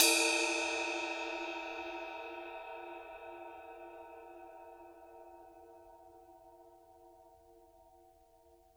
susCymb1-hitstick_mp_rr2.wav